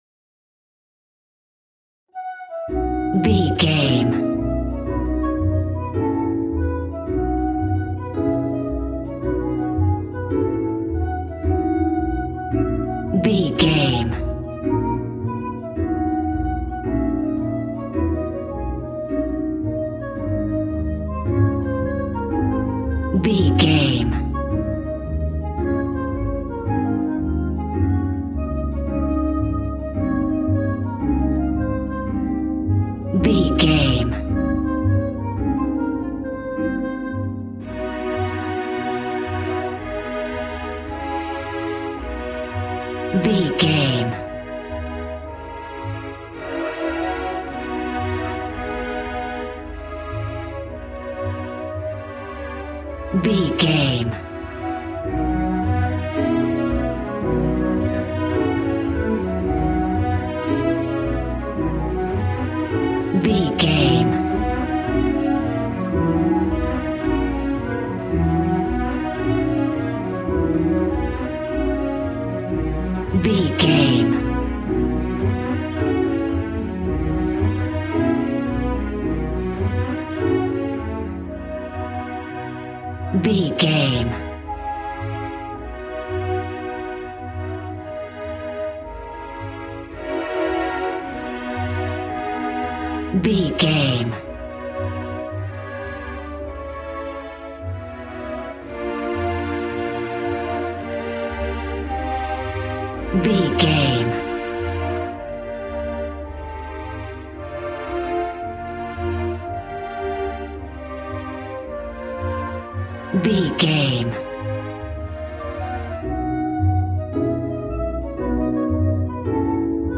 Aeolian/Minor
E♭
dramatic
epic
percussion
violin
cello